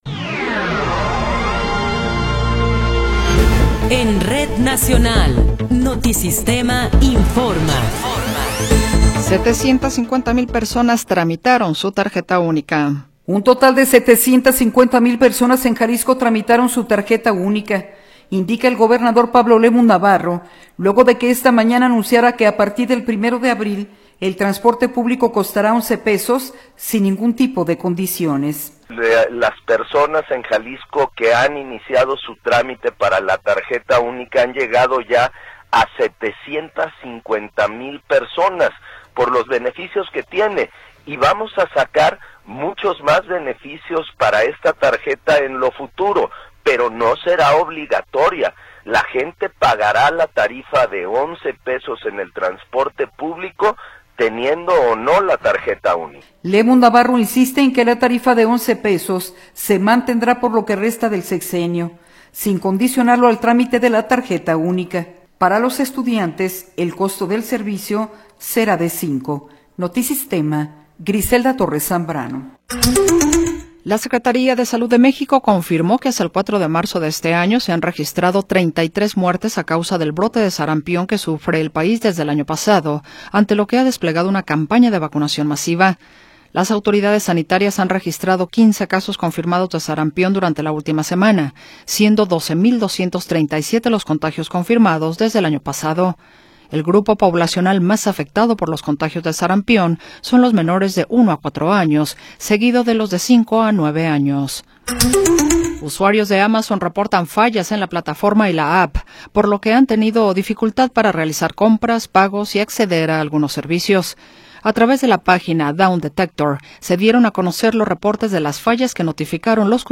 Noticiero 17 hrs. – 5 de Marzo de 2026
Resumen informativo Notisistema, la mejor y más completa información cada hora en la hora.